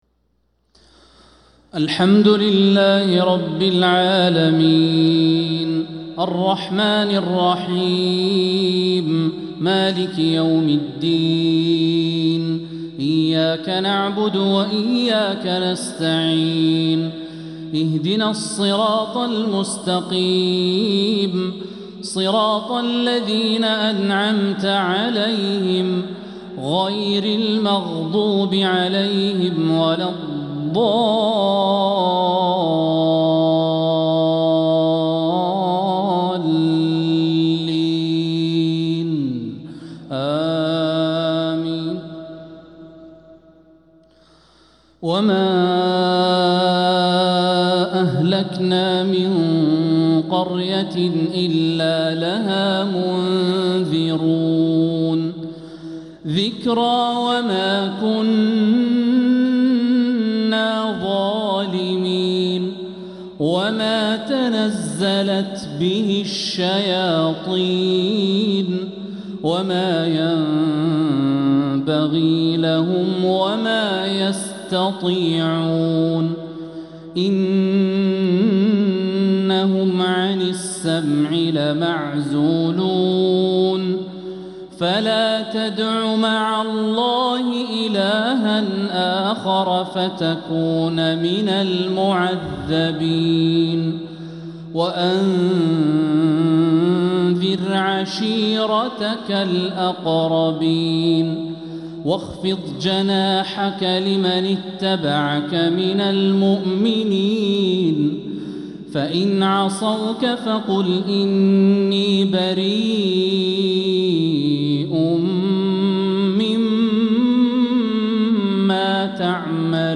عشاء الثلاثاء 7-7-1446هـ خواتيم سورة الشعراء 208-227 | Isha prayer from surah Ash-Shu'araa 7-1-2025 🎙 > 1446 🕋 > الفروض - تلاوات الحرمين